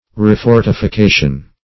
refortification.mp3